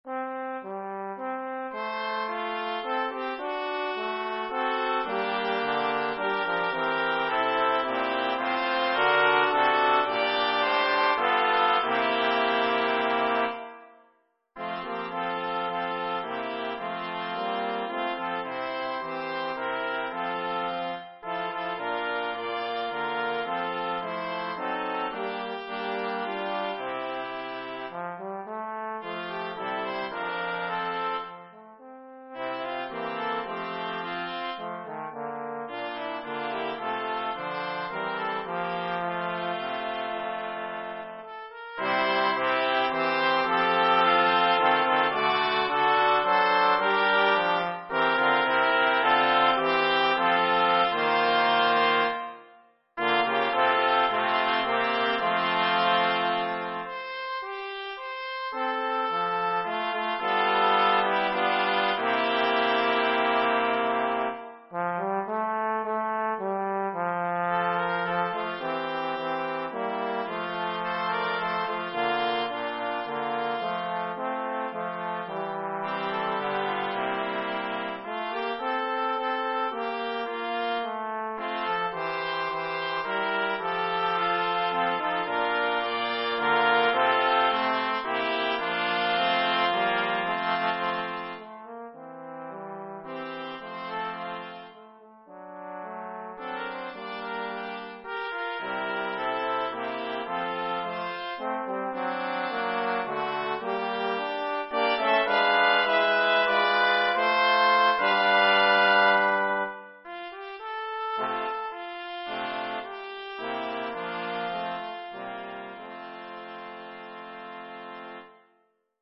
Brass quartet
Song (binary)
(2-tp, 2-trb, opt horn and Baritone horn treble clef)